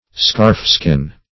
scarfskin - definition of scarfskin - synonyms, pronunciation, spelling from Free Dictionary Search Result for " scarfskin" : The Collaborative International Dictionary of English v.0.48: Scarfskin \Scarf"skin`\, n. (Anat.) See Epidermis .